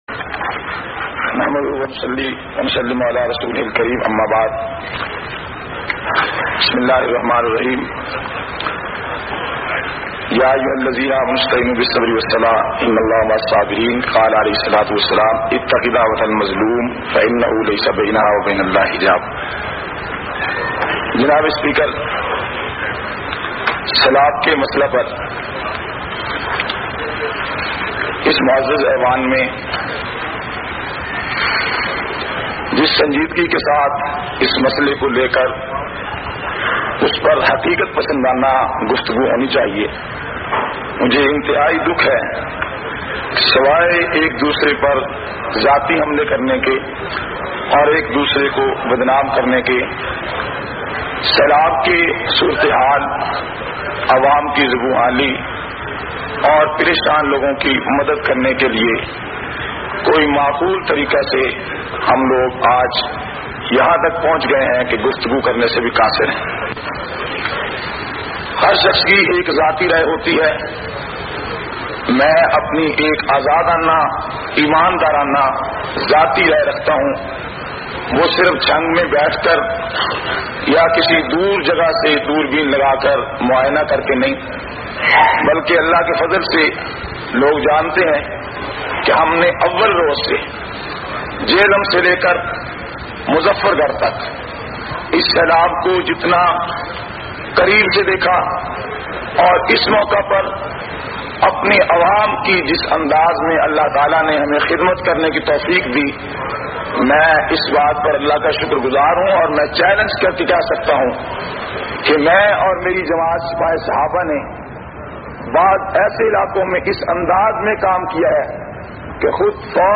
161- Jehlum me Selab ki tabahi aur usky Zimmadar kon Assembly Khitab.mp3